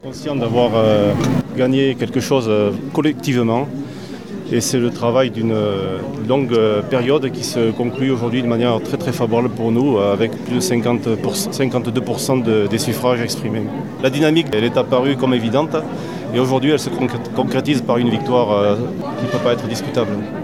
Quelques minutes après l’annonce des résultats, devant une foule venue assister au dépouillement final, le vainqueur, Patrice Saint-Léger a réagi aux résultats.